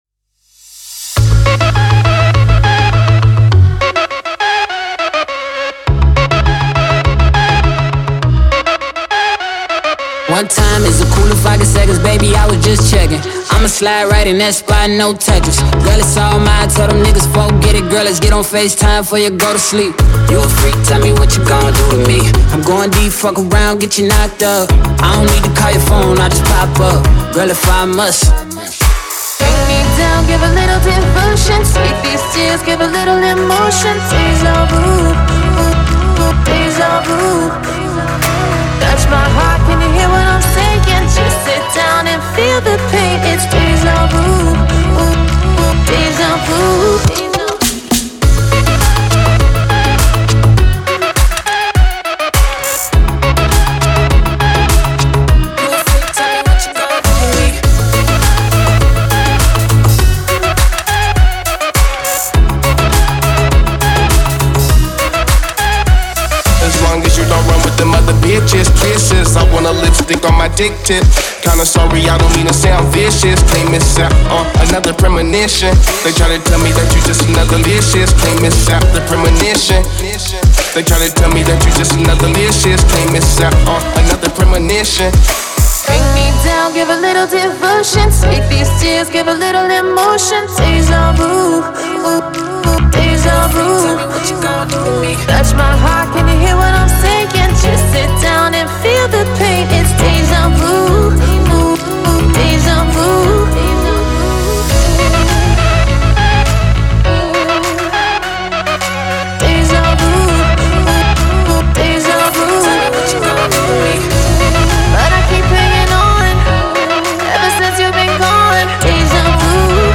это современная R&B композиция